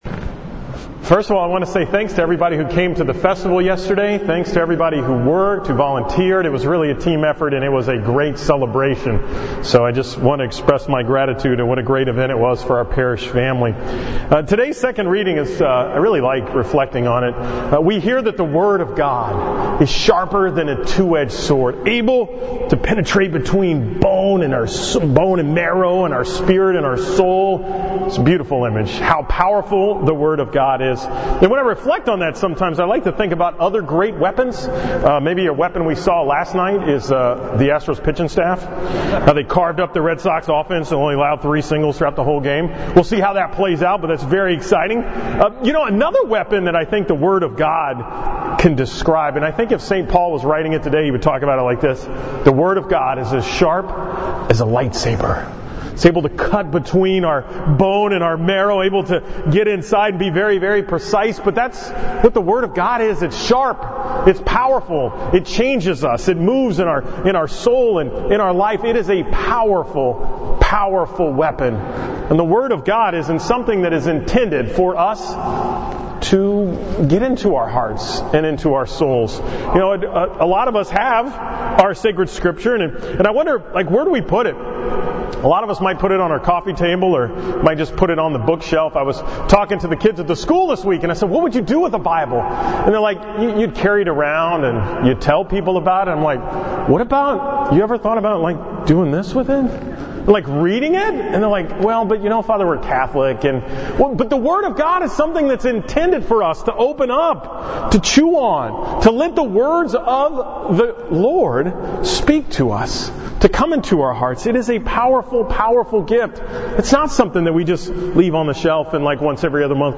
From the 11 am Mass on October 14, 2018